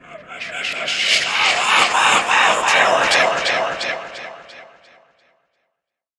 StalkerAssassinWhisperingE.ogg